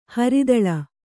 ♪ haridaḷa